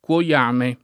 cuoiame [ k U o L# me ]